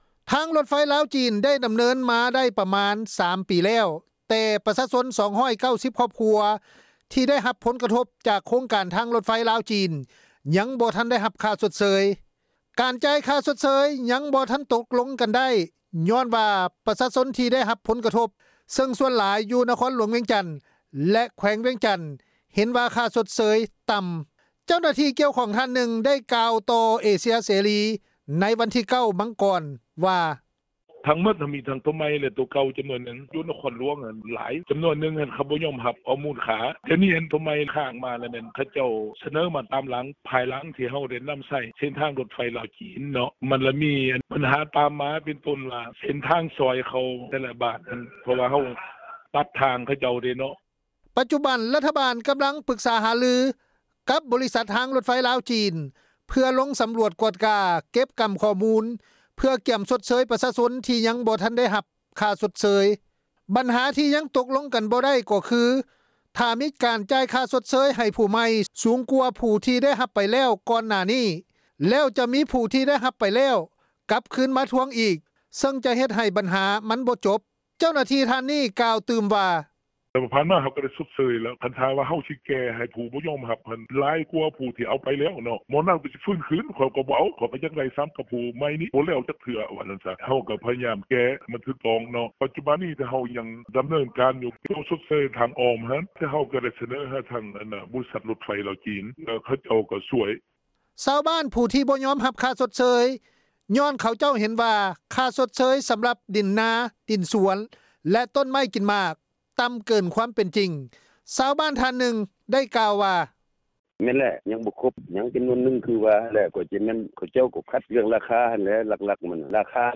ທາງລົດໄຟລາວ-ຈີນ ໄດ້ດໍາເນີນການມາ ໄດ້ປະມານ 3 ປີ ແລ້ວ, ແຕ່ ປະຊາຊົນ 290 ຄອບຄົວ ທີ່ໄດ້ຮັບຜົນກະທົບ ຈາກໂຄງການ ທາງລົດໄຟລາວ-ຈີນ ຍັງບໍ່ທັນໄດ້ຮັບຄ່າຊົດເຊີຍ. ການຈ່າຍຊົດເຊີຍ ຍັງບໍ່ທັນຕົກລົງກັນໄດ້ ຍ້ອນວ່າ ປະຊາຊົນ ທີ່ໄດ້ຮັບຜົນກະທົບ, ເຊິ່ງສ່ວນຫຼາຍ ຢູ່ນະຄອນຫຼວງວຽງຈັນ ແລະ ແຂວງວຽງຈັນ ເຫັນວ່າ ຄ່າຊົດເຊີຍຕໍ່າ. ເຈົ້າໜ້າທີ່ ກ່ຽວຂ້ອງທ່ານໜຶ່ງ ໄດ້ກ່າວຕໍ່ ວິທຍຸເອເຊັຽເສຣີ ໃນວັນທີ 9 ມັງກອນ ວ່າ:
ຊາວບ້ານ ຜູ້ທີ່ບໍ່ຍອມຮັບ ຄ່າຊົດເຊີຍ ຍ້ອນເຂົາເຈົ້າເຫັນວ່າ ຄ່າຊົດເຊີຍ ສໍາລັບດິນນາ-ດິນສວນ ແລະ ຕົ້ນໄມ້ກິນໝາກ ຕໍ່າເກີນຄວາມເປັນຈິງ. ຊາວບ້ານທ່ານໜຶ່ງ ໄດ້ກ່າວວ່າ:
ໂຄງການທາງລົດໄຟລາວ-ຈີນ ຍັງບໍ່ທັນຈ່າຍຄົບ ຍ້ອນວ່າ ຊາວບ້ານ ເຫັນວ່າ ຄ່າຊົດເຊີຍ ມັນຕໍ່າຈົນເກີນໄປ ແລະ ກາຍເປັນເລື່ອງຄ້າງຄາ ມາຈົນເຖິງປັດຈຸບັນ. ເຈົ້າໜ້າທີ່ທ້ອງຖິ່ນ ທ່ານໜຶ່ງ ໄດ້ກ່າວວ່າ: